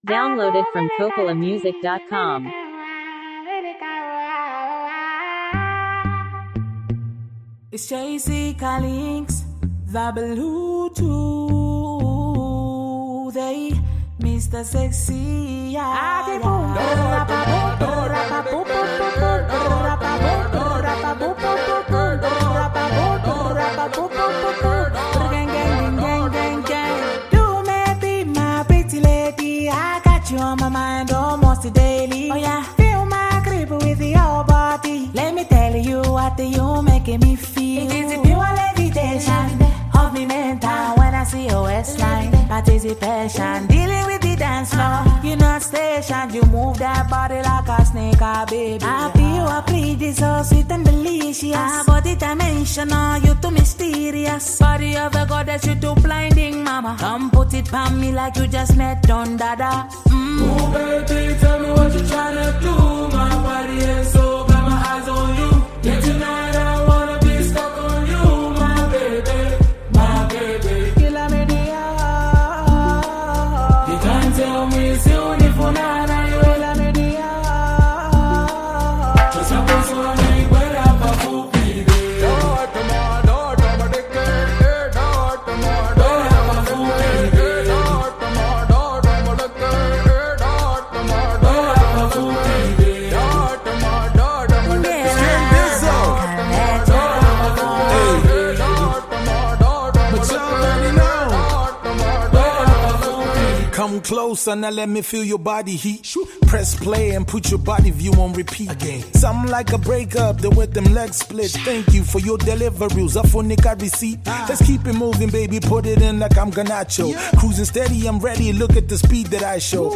a smooth, feel-good love song